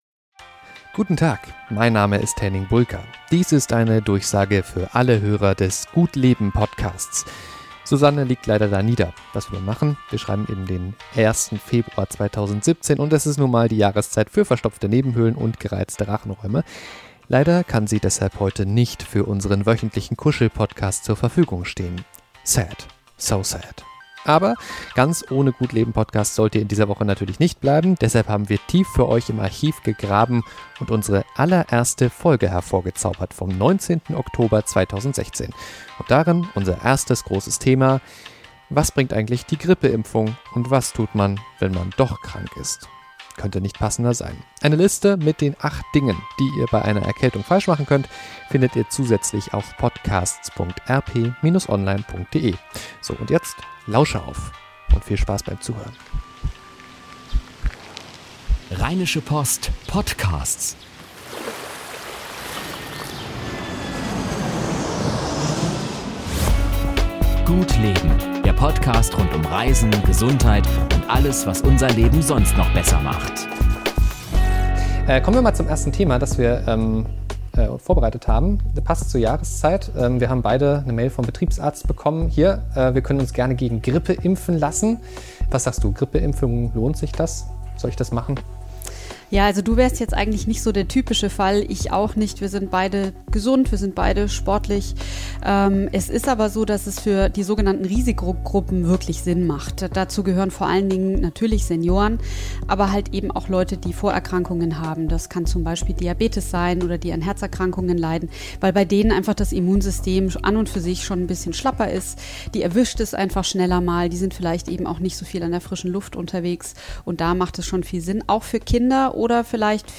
Intromusik: "Local Forecast -